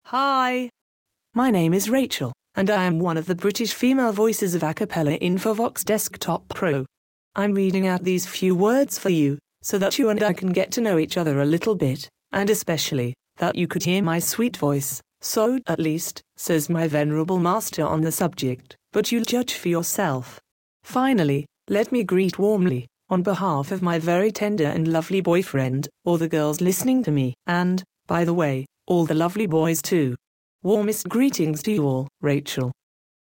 Texte de démonstration lu par Rachel, voix féminine anglaise d'Acapela Infovox Desktop Pro
Écouter la démonstration de Rachel, voix féminine anglaise d'Acapela Infovox Desktop Pro